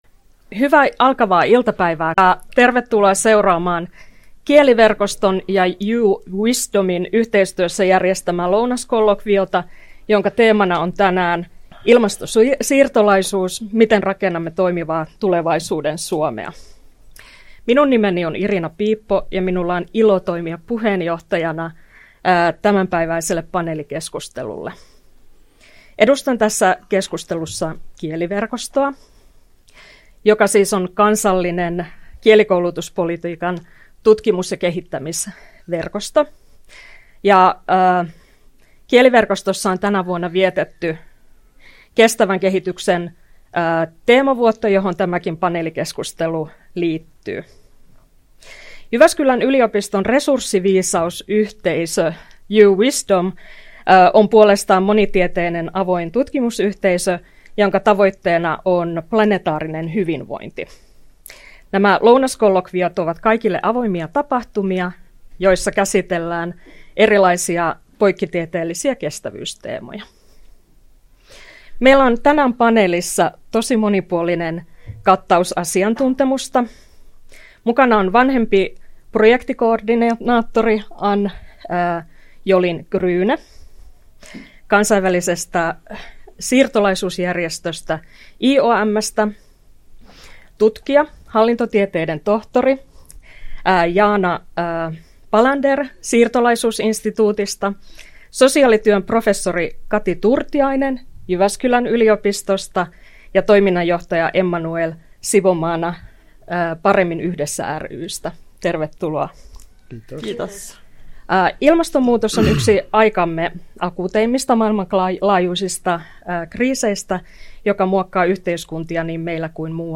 JYU.Wisdomin ja Kieliverkoston lounaskollokvio 20.11.2024